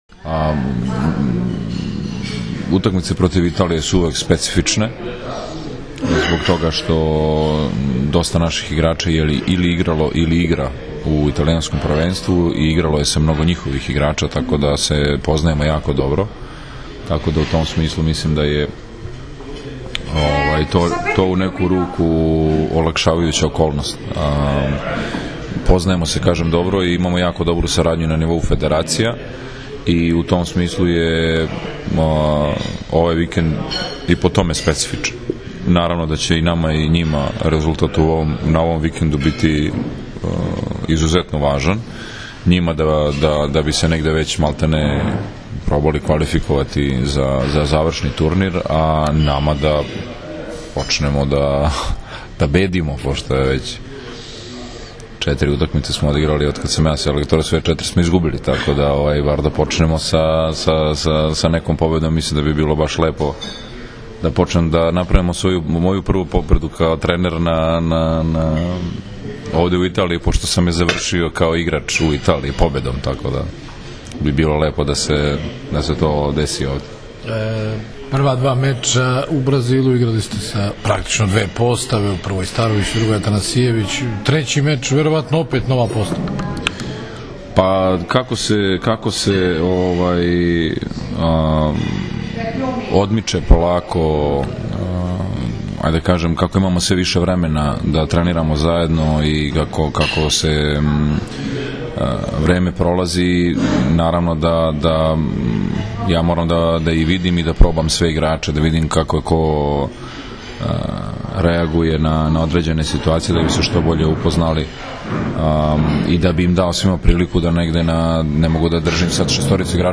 U Pezaru je danas održana konferencija za novinare uoči prve utakmice II vikenda A grupe XXVI Svetske lige 2015. između Srbije i Italije, kojoj su prisustvovali kapiteni i treneri obe selekcije: Dragan Stanković i Nikola Grbić, odnosno Dragan Travica i Mauro Beruto.
IZJAVA NIKOLE GRBIĆA